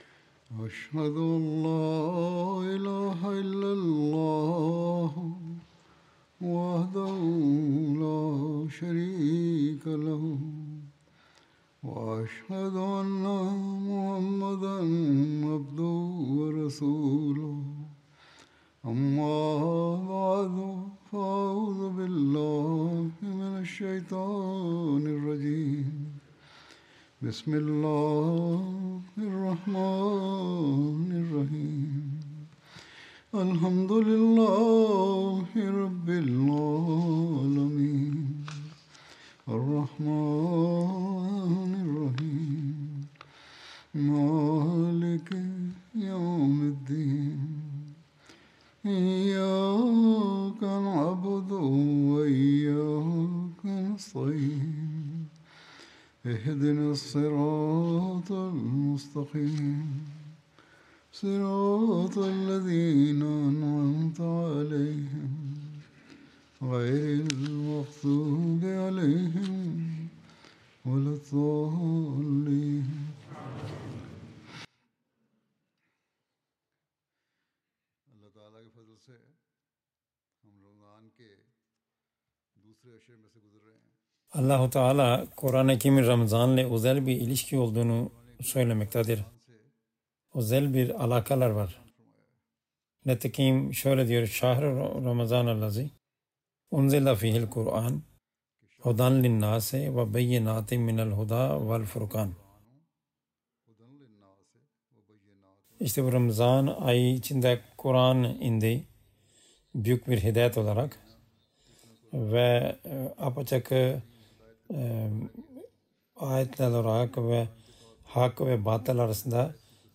Turkish Translation of Friday Sermon delivered by Khalifatul Masih